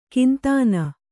♪ kintāna